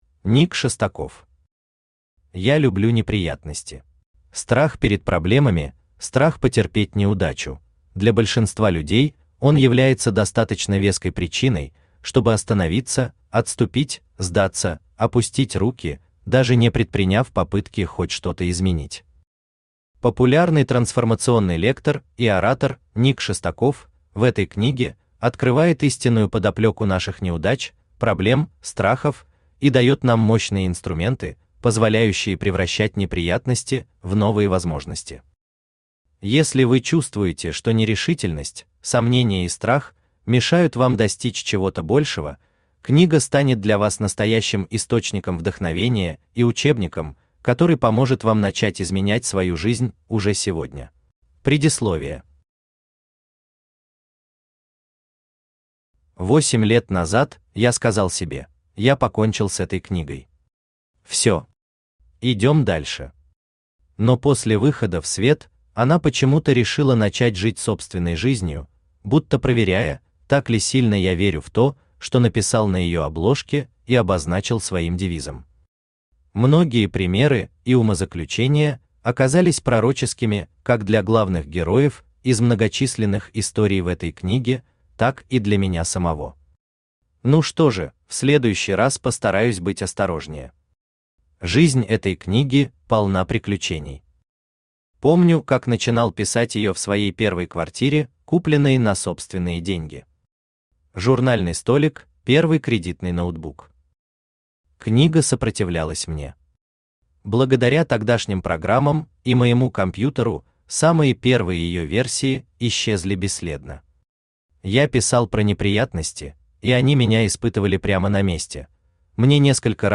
Аудиокнига Я люблю неприятности | Библиотека аудиокниг
Aудиокнига Я люблю неприятности Автор Ник Шестаков Читает аудиокнигу Авточтец ЛитРес.